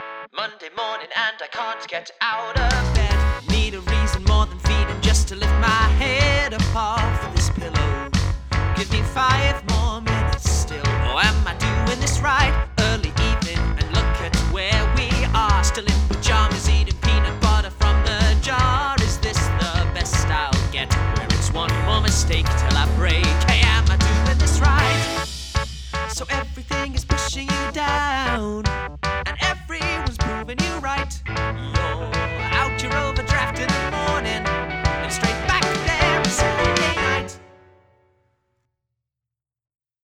There’ll be a range of different styles in the album, ranging from really modern songs that sound like Ariana Grande or Coldplay, mixed with songs that sound very musical theatre.
I've written a short demo of the Opening Song for you all to hear (below). Millennials (Short Demo) What made you want to apply for the Creative Fund?